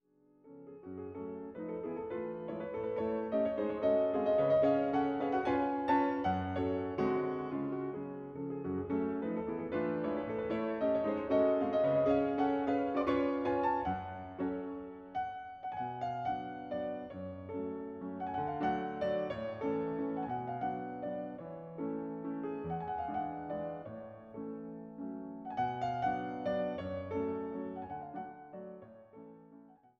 Mazurka cis-Moll op. 50 Nr. 3